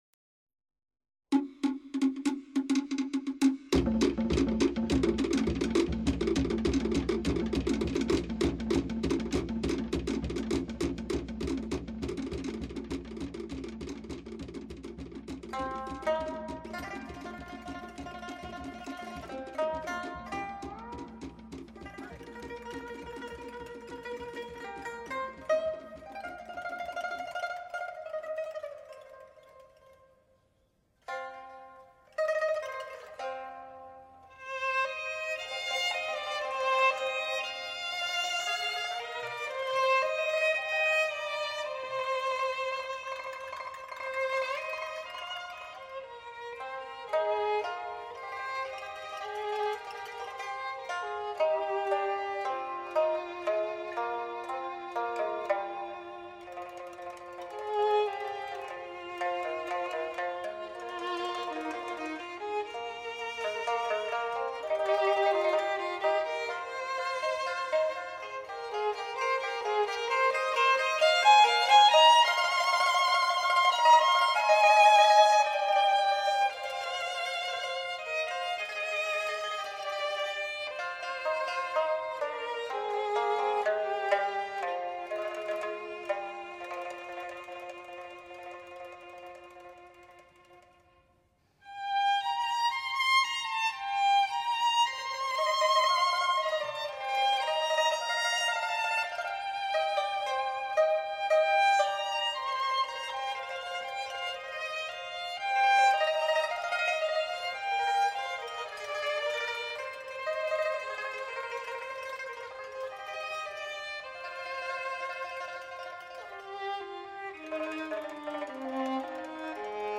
小提琴 管弦乐
旋律优美、伸展、抒情、平缓.